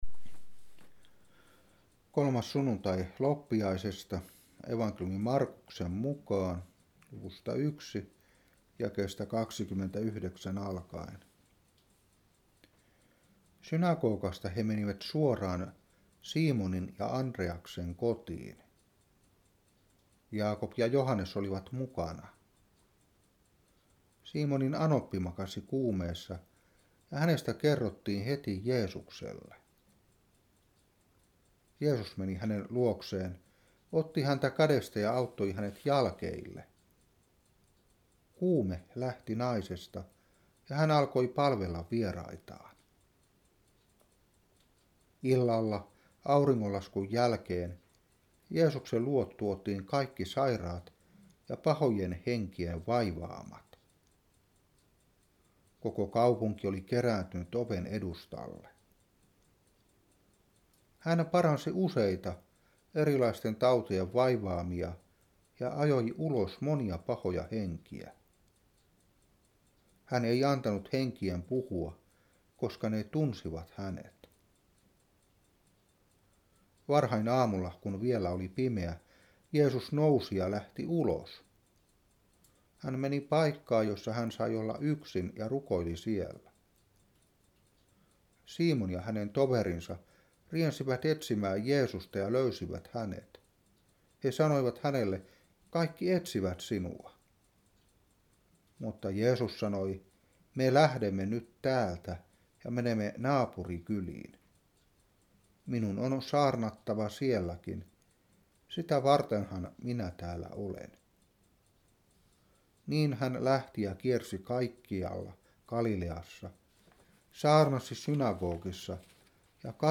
Saarna 2012-1.